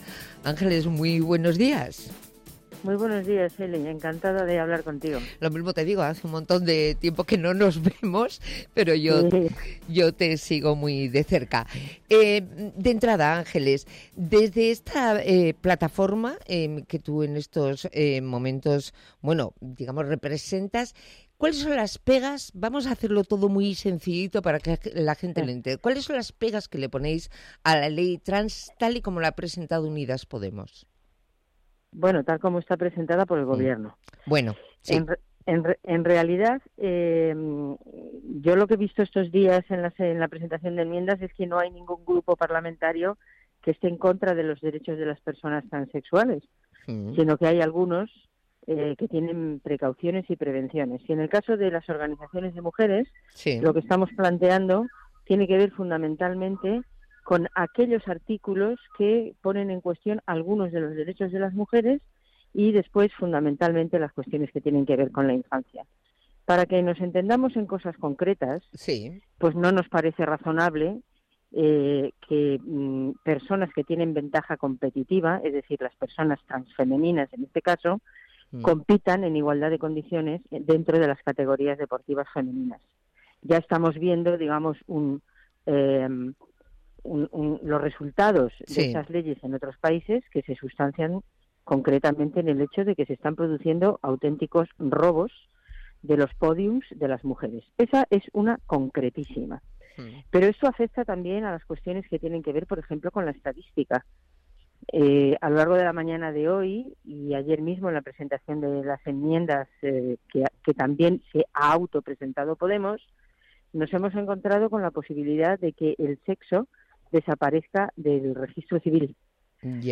En el Buenos Días Madrid de Onda Madrid